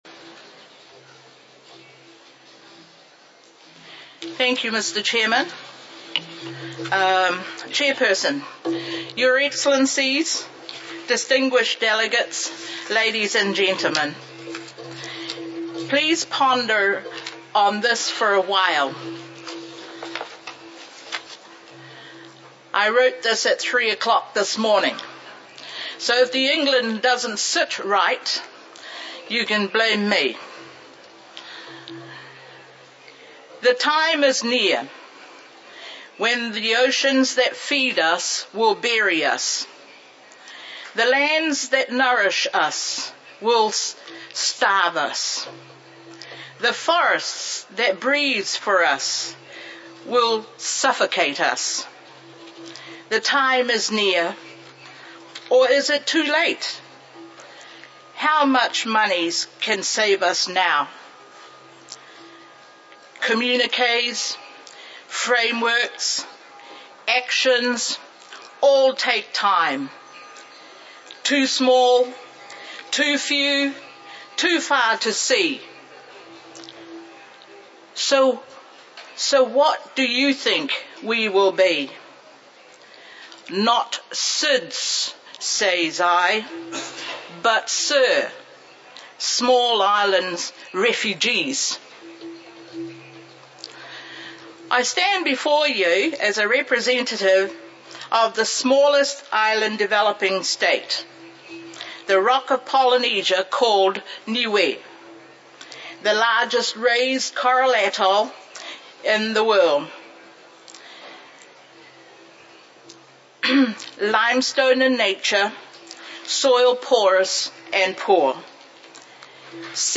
Statements by Heads of Delegations under Item 10:
Ms O'Love Tauveve Jacobsen, High Commissioner of Niue in New Zealand